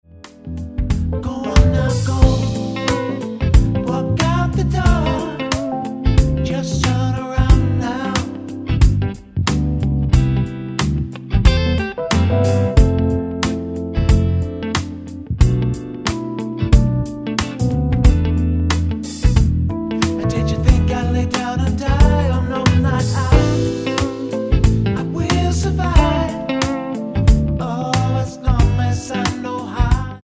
Tonart:Am Ohne Trombone mit Chor